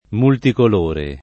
multicolore
multicolore [ multikol 1 re ]